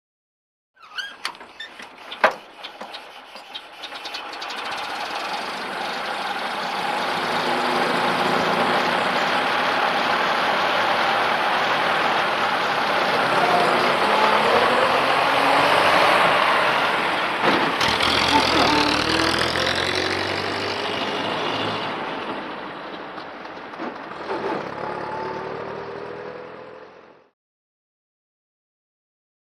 Automobile; Start / Away; Vintage Dennis Truck Start Up And Away With Gear Grinds.